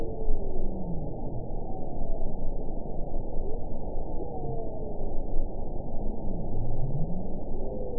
event 922418 date 12/31/24 time 21:27:35 GMT (5 months, 2 weeks ago) score 9.20 location TSS-AB06 detected by nrw target species NRW annotations +NRW Spectrogram: Frequency (kHz) vs. Time (s) audio not available .wav